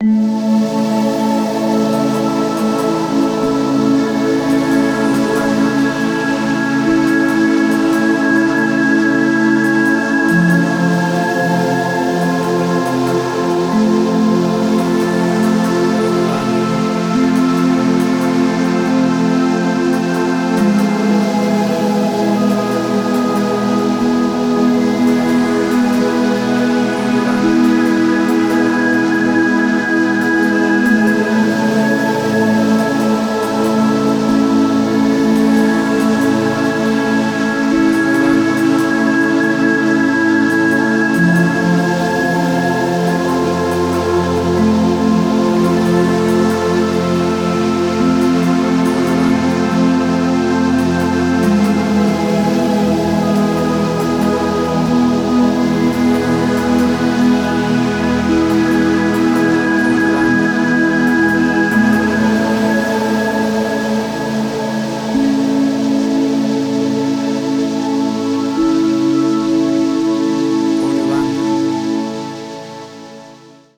WAV Sample Rate: 16-Bit stereo, 44.1 kHz